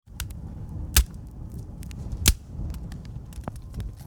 Category: Nature Mood: Warm Editor's Choice
Donate MP3 MEMBER WAV Downloads: 0 Likes: 0 Back: Content License: Sound Effects Share: Facebook X (Twitter) WhatsApp LinkedIn Pinterest Copy link